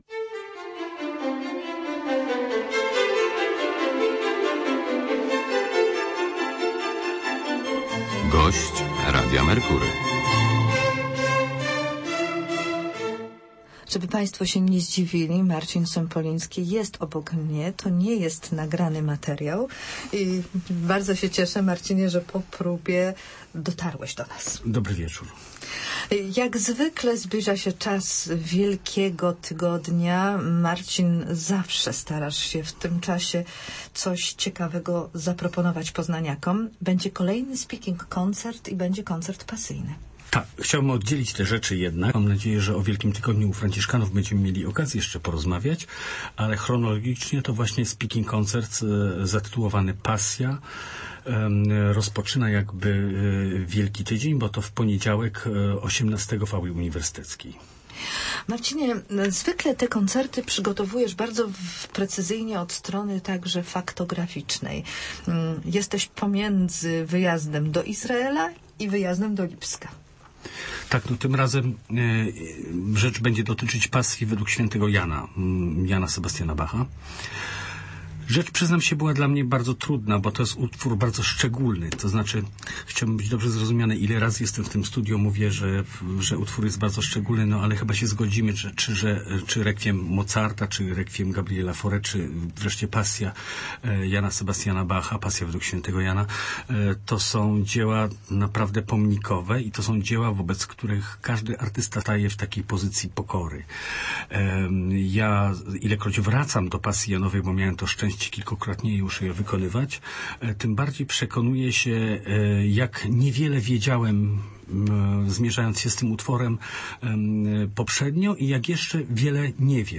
Usłyszmy fragmenty z Pasji Jana Sebastiana Bacha.